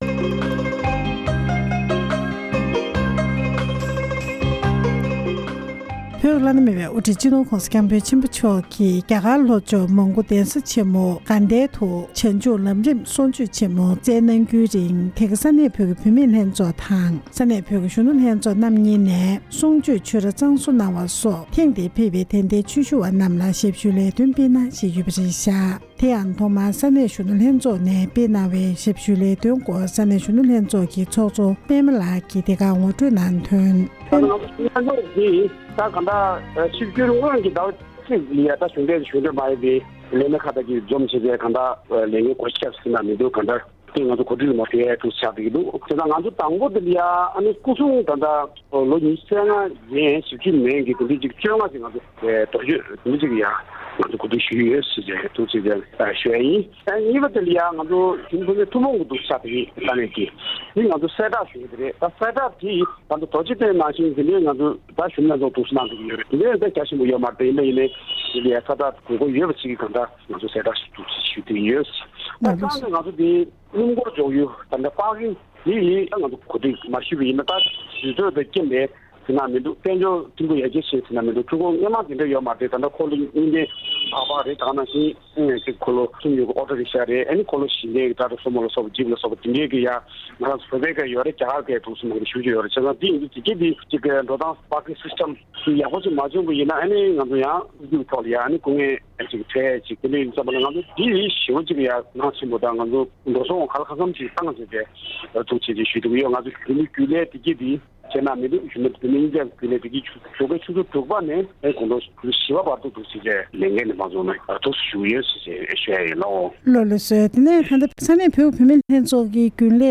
འབྲེལ་ཡོད་མི་སྣར་གནས་འདྲི་ཞུས་པ་ཞིག་ལ་གསན་རོགས་ཞུ༎